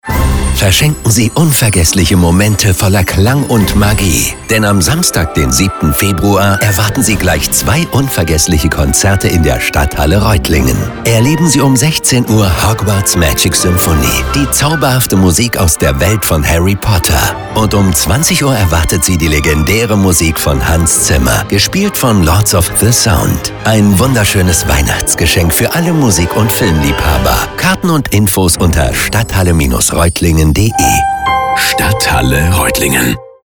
Dabei setzen wir konsequent auf echte Sprecherinnen und Sprecher – keine KI-Stimmen.
Energiegeladen, mitreißend, aktivierend.
Stadthalle-Reutlingen-Xmas-Gutscheine-33s-Musik-2.mp3